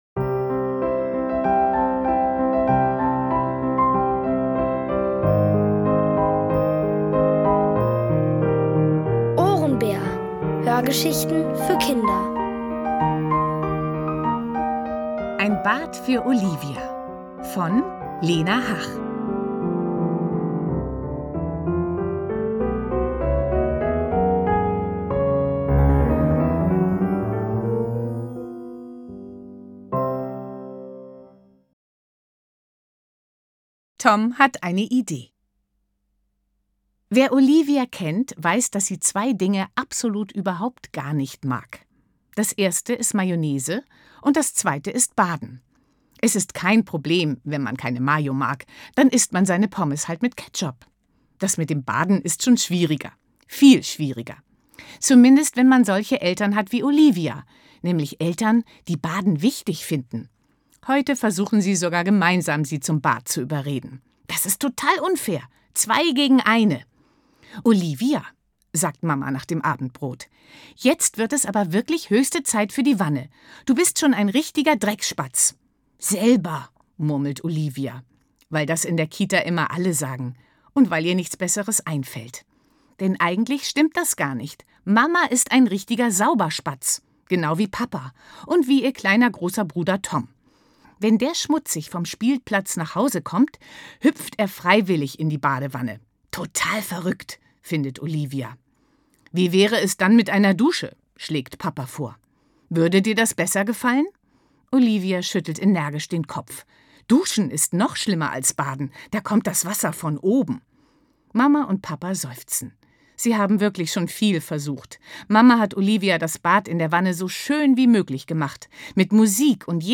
Von Autoren extra für die Reihe geschrieben und von bekannten Schauspielern gelesen.
Kinder & Familie